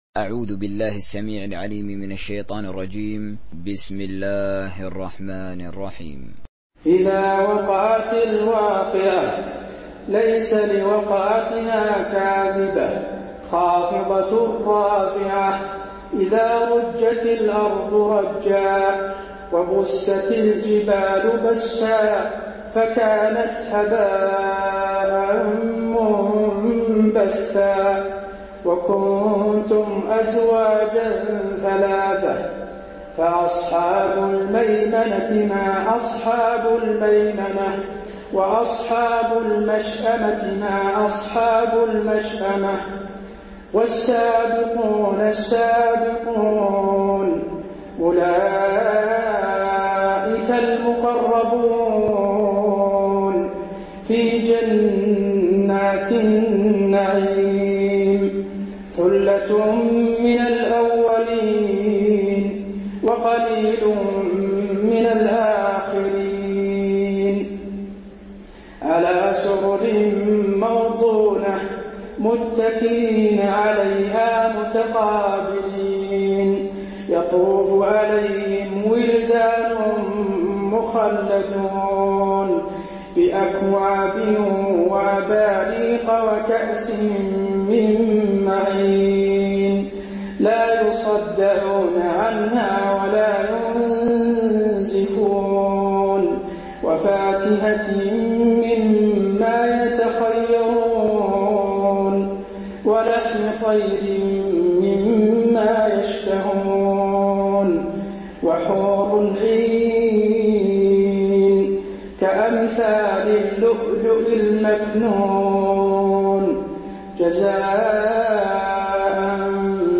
دانلود سوره الواقعه mp3 حسين آل الشيخ تراويح روایت حفص از عاصم, قرآن را دانلود کنید و گوش کن mp3 ، لینک مستقیم کامل
دانلود سوره الواقعه حسين آل الشيخ تراويح